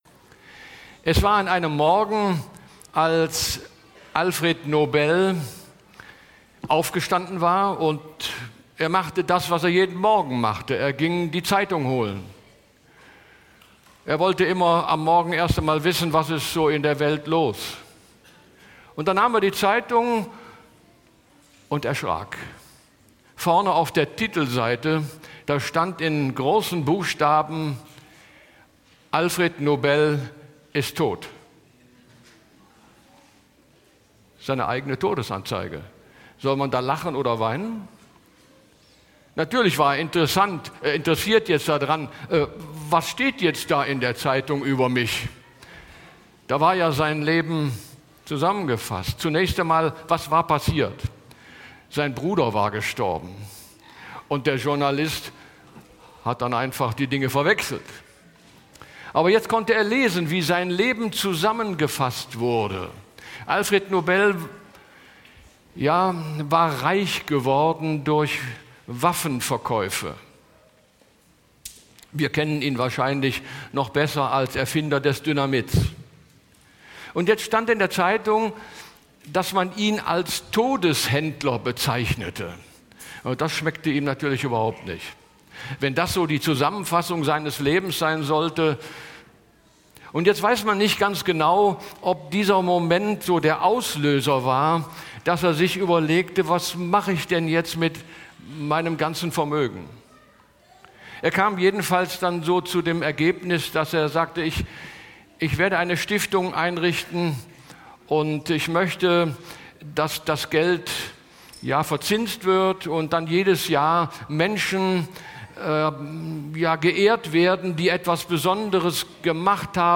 Mitschnitt